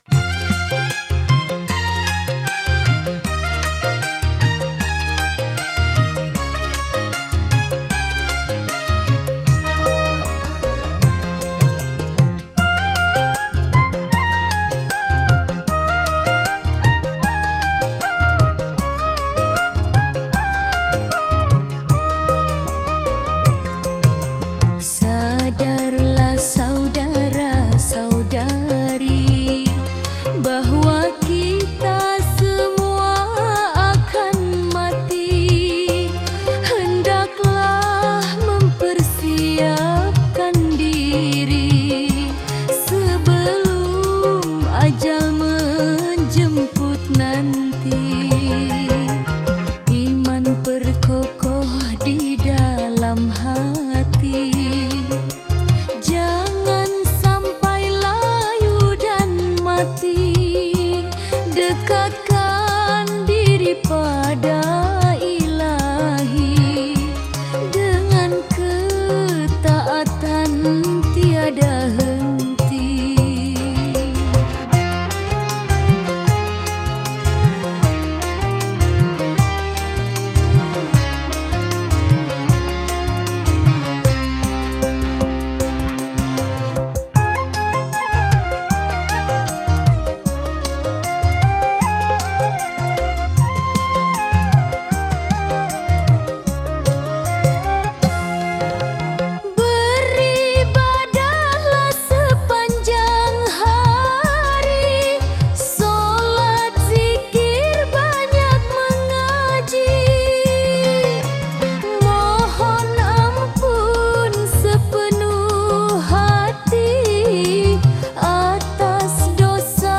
Dengar lantunan lagu yang syahdu dan menyentuh hati dari syair tersebut